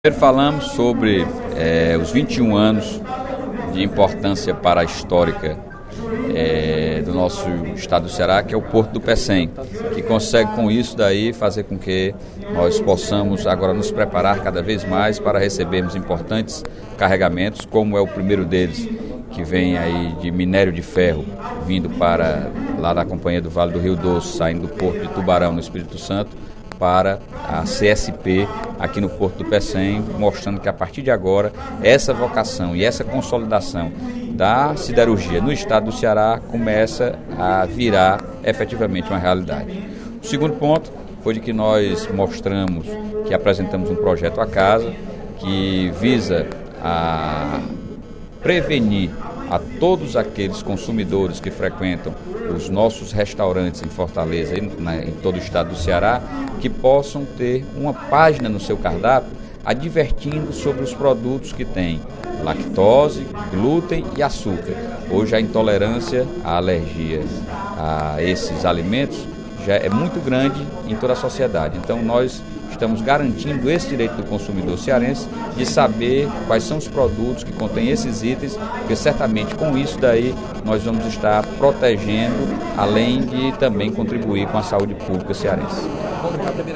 O deputado Sérgio Aguiar (Pros) ressaltou, nesta quinta-feira (25/02), durante o primeiro expediente da sessão plenária, os 21 anos de criação do Porto do Pecém, caracterizado como um dos principais responsáveis para o desenvolvimento econômico do Ceará.